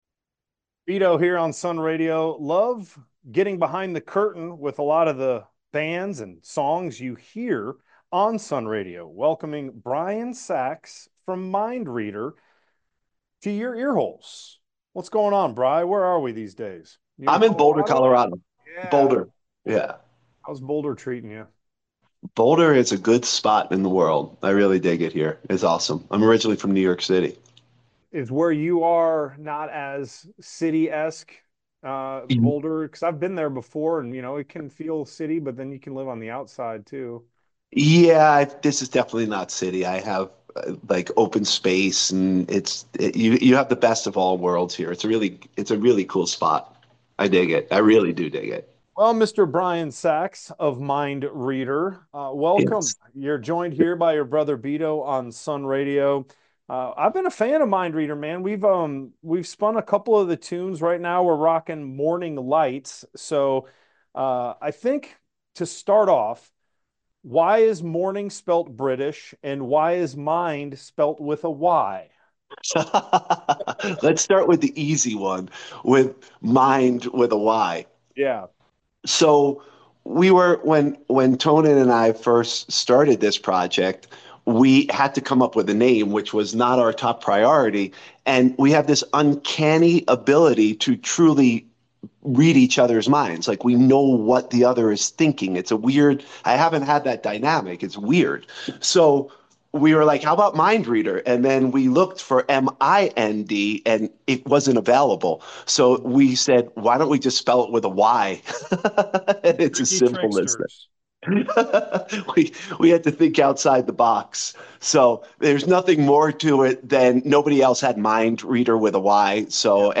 Mynd Reader Interview
mynd-reader-interview.mp3